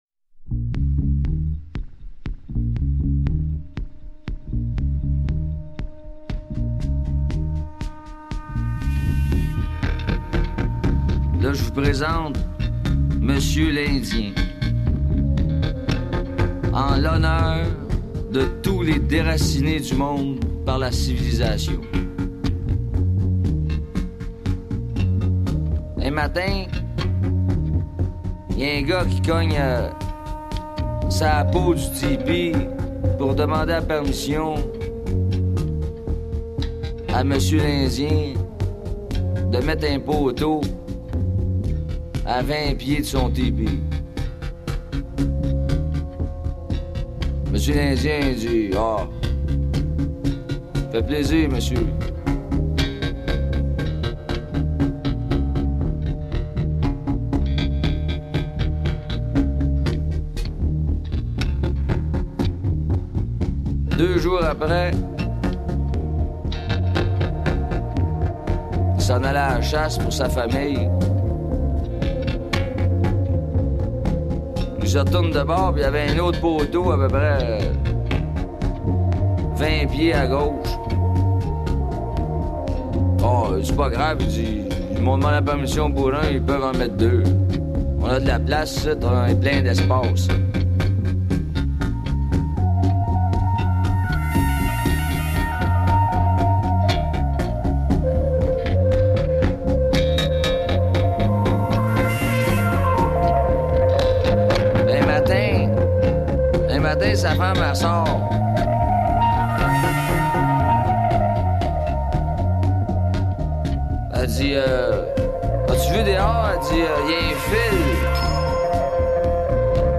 Trippy, hypnotic, unusual item !